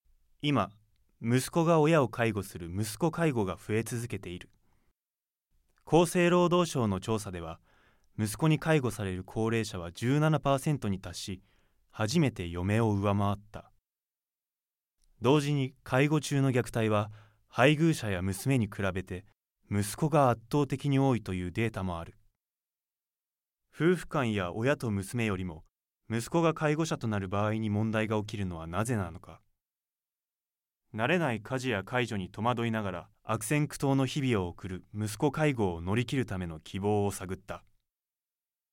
芯が通っている一方、誠実で優しい声質が特徴です。ナレーション全般を得意としています。